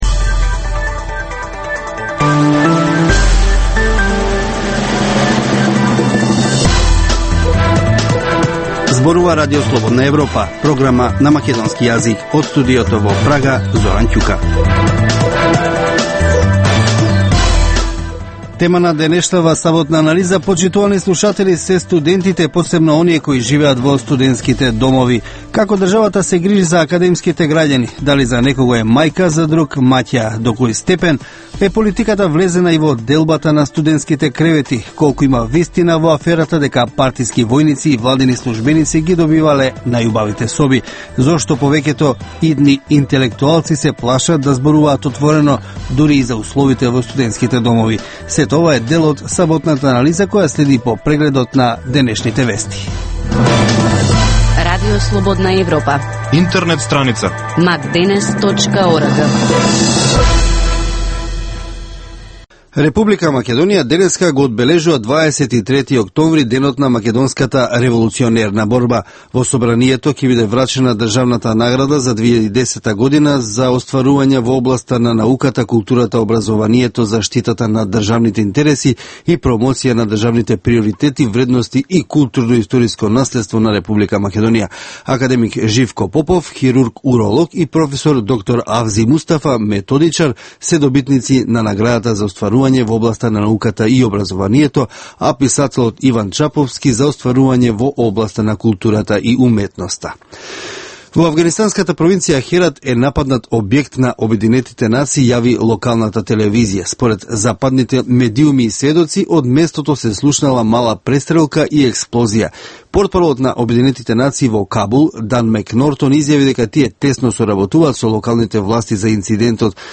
Информативна емисија, секој ден од Студиото во Прага. Топ вести, теми и анализи од Македонија, регионот и светот. Во Вестите во 12 часот доминантни се актуелните теми од политиката и економијата.